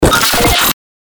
FX-1908-PHONE-BREAKER
FX-1908-PHONE-BREAKER.mp3